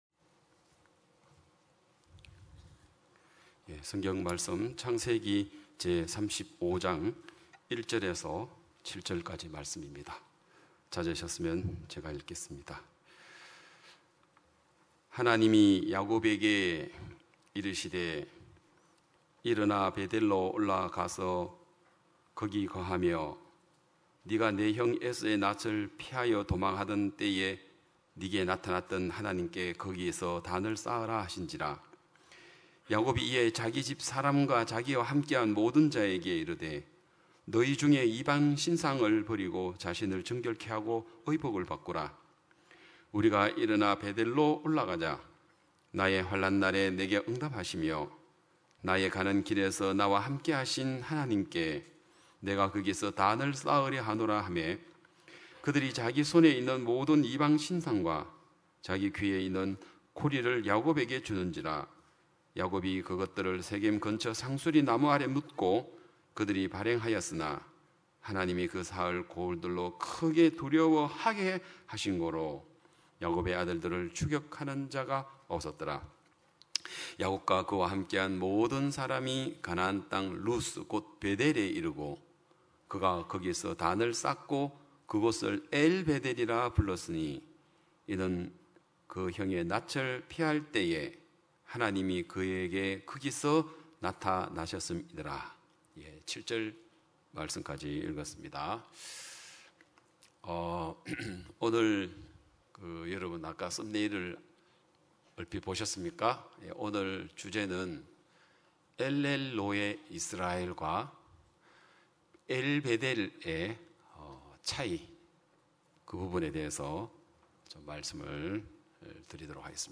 2022년 9월 25일 기쁜소식양천교회 주일오전예배